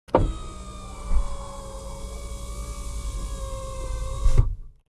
جلوه های صوتی
دانلود صدای ماشین 26 از ساعد نیوز با لینک مستقیم و کیفیت بالا